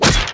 拳头击中木制盔甲zth070523.wav
通用动作/01人物/03武术动作类/拳头击中木制盔甲zth070523.wav
• 声道 單聲道 (1ch)